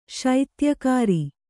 ♪ śaityakāri